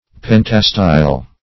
Search Result for " pentastyle" : The Collaborative International Dictionary of English v.0.48: Pentastyle \Pen"ta*style\, a. [Penta- + Gr.